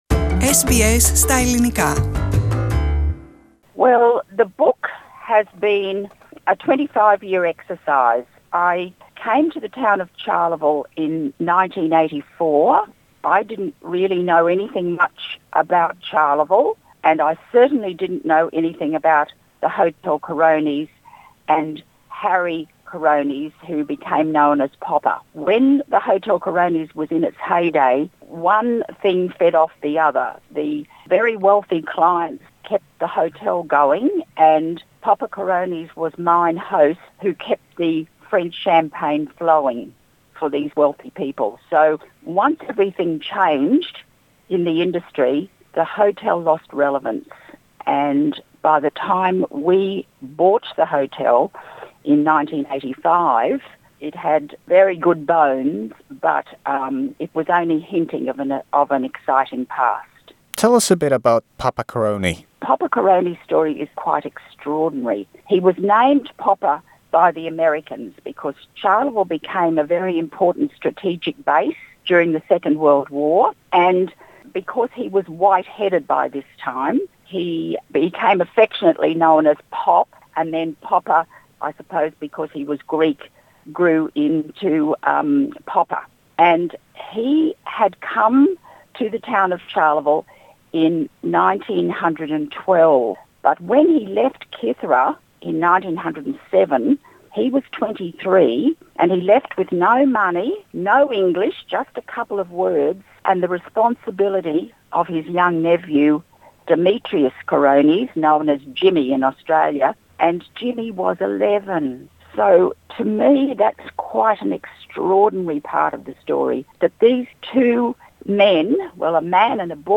SBS Greek